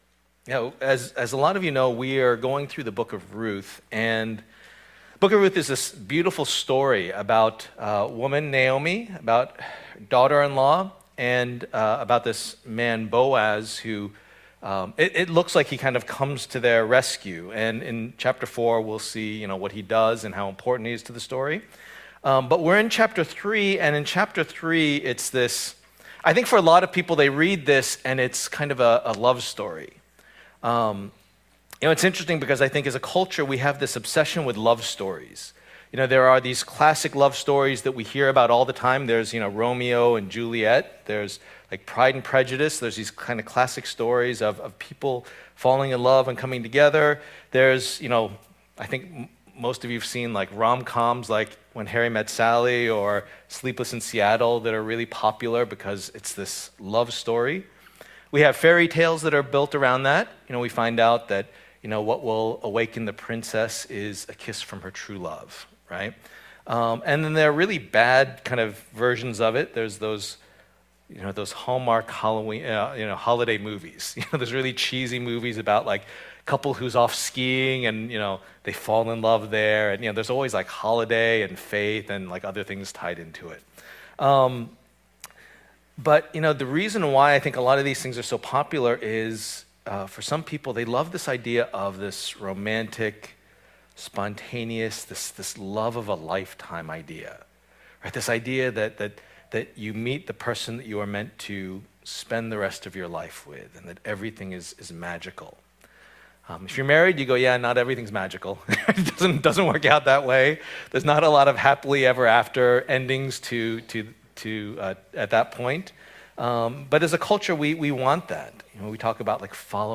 Passage: Ruth 3:1-18 Service Type: Lord's Day